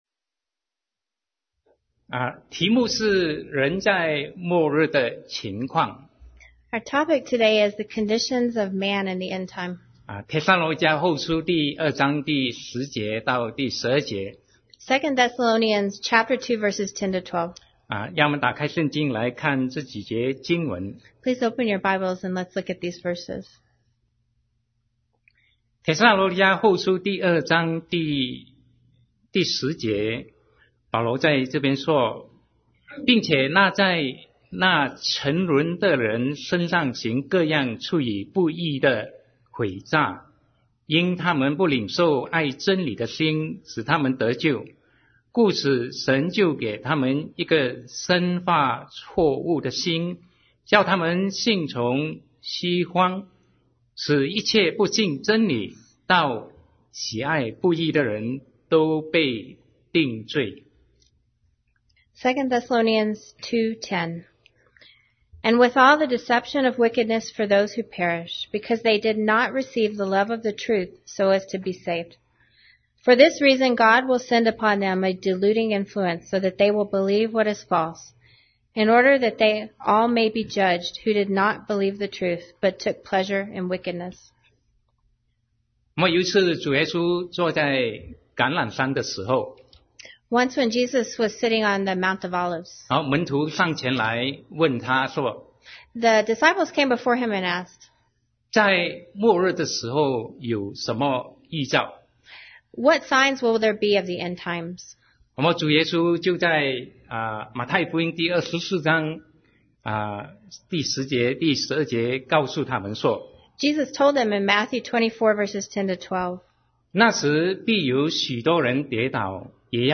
Sermon 2017-10-01 The Conditions of Man in the End Time